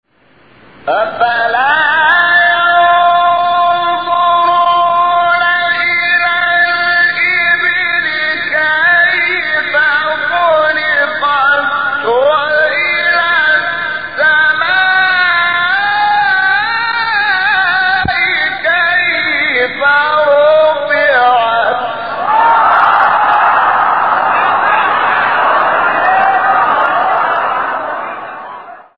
سوره : غاشیه آیه: 17-18 استاد : شحات محمد انور مقام : حجاز قبلی بعدی